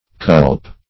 Search Result for " culpe" : The Collaborative International Dictionary of English v.0.48: Culpe \Culpe\ (k[u^]lp), n. [F. coulpe, fr.L.culpa.]